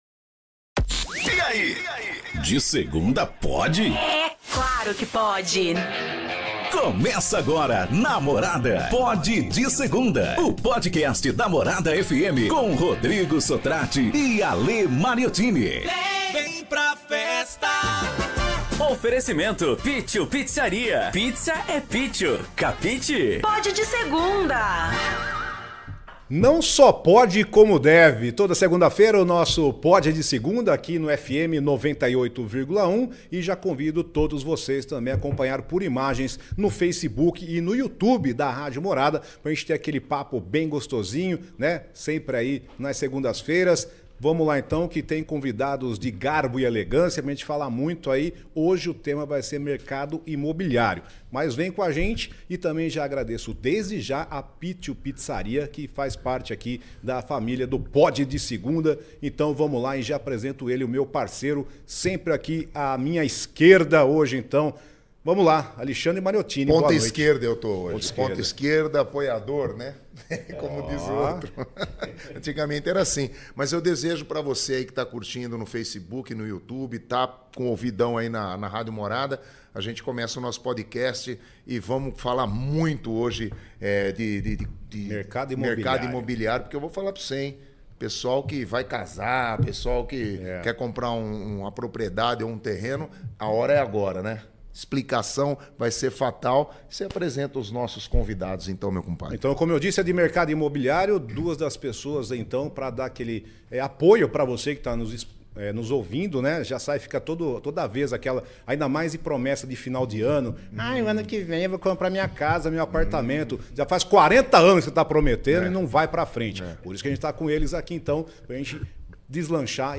Um papo descontraído, muita música e histórias de quem vive o mundo sertanejo!